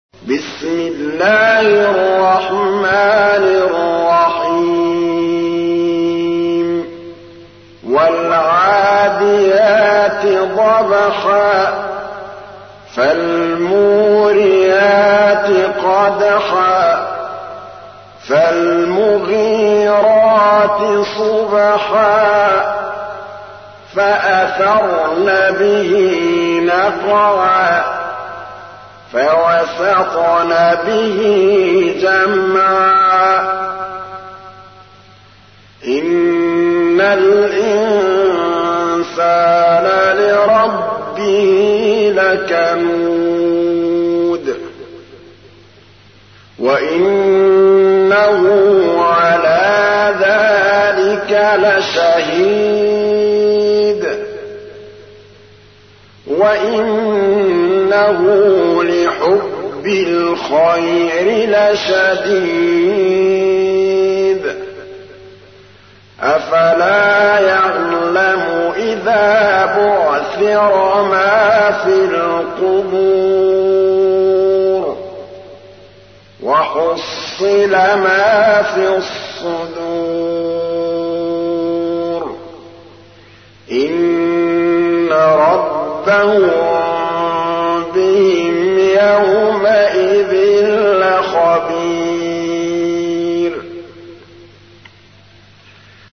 تحميل : 100. سورة العاديات / القارئ محمود الطبلاوي / القرآن الكريم / موقع يا حسين